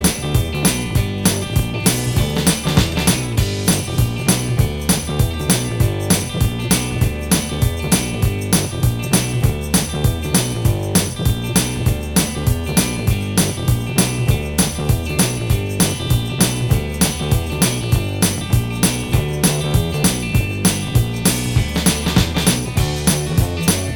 Minus Lead Guitar Rock 3:33 Buy £1.50